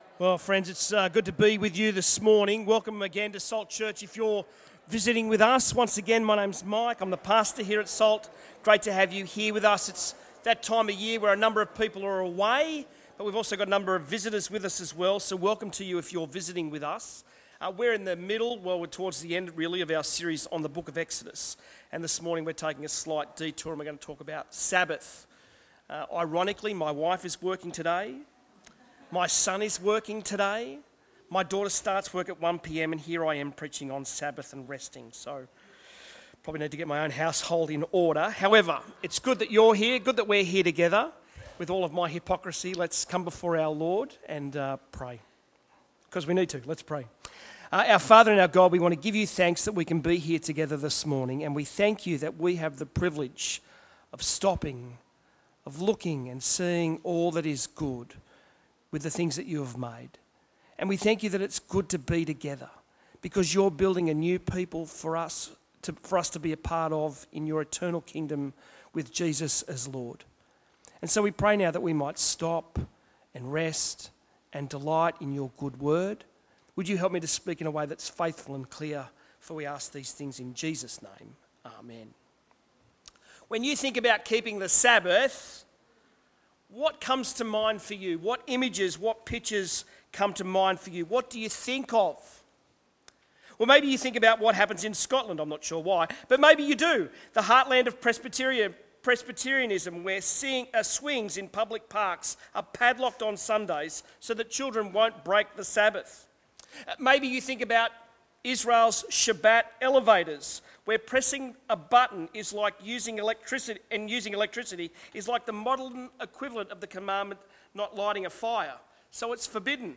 Sermons
Bible talk on Exodus from God's Dangerous Deliverance Series